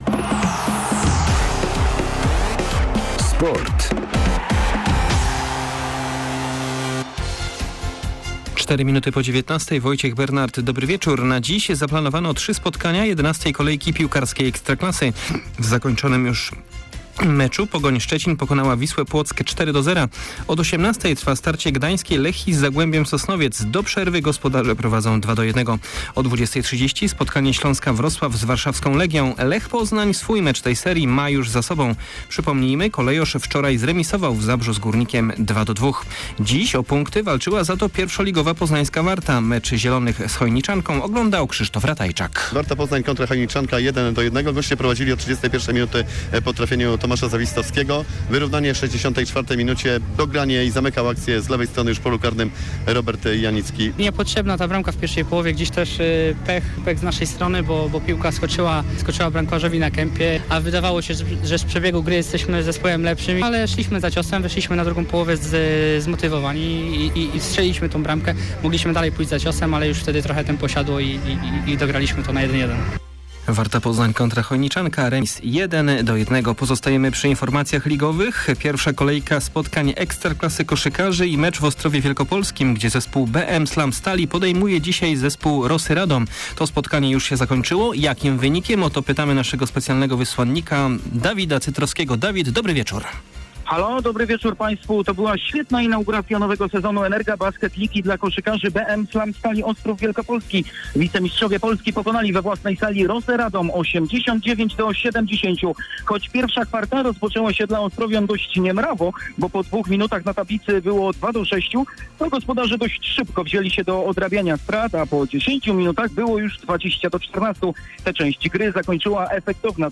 06.10. SERWIS SPORTOWY GODZ. 19:05